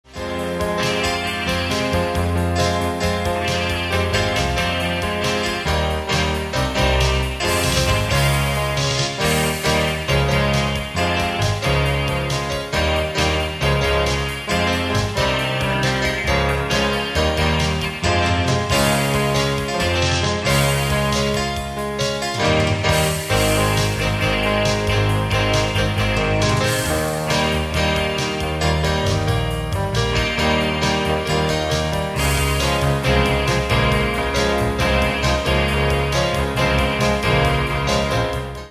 Rock Ballad
There are as yet no lyrics.